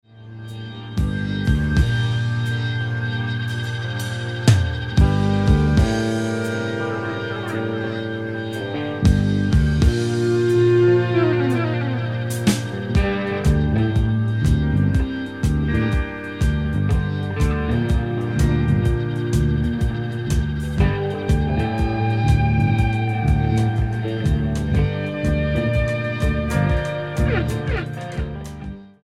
STYLE: Hard Music
the third album from Sweden's rock/metal band